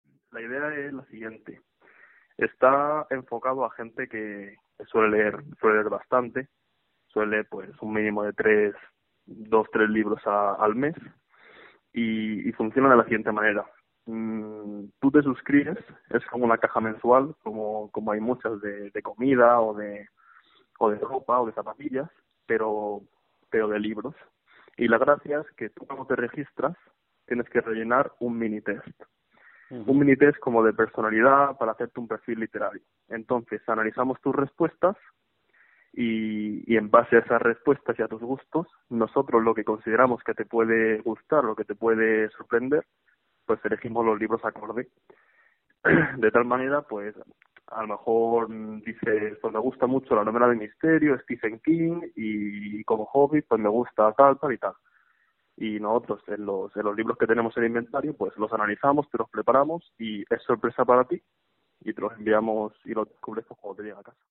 Entrevistas Ampliar Crea su empresa con 18 años, y es cultural y solidaria facebook twitter google+ Comentar Imprimir Enviar Add to Flipboard Magazine.